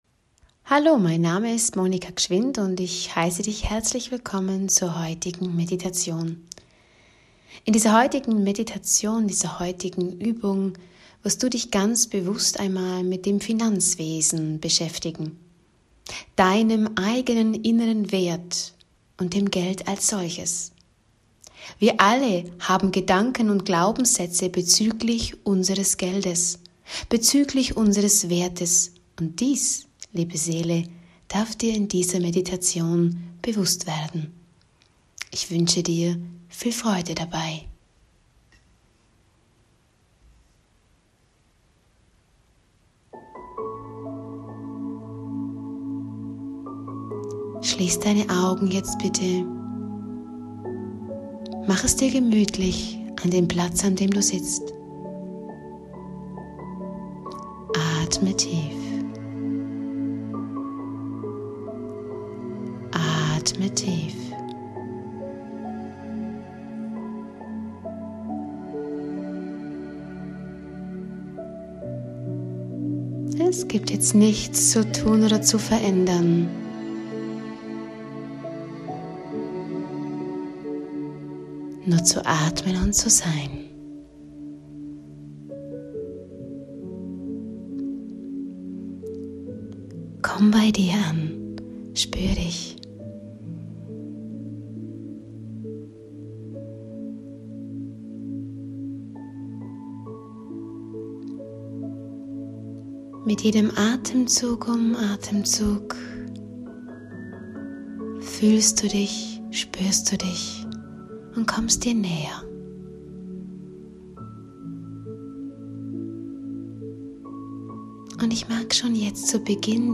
07-Meditation-Geld.mp3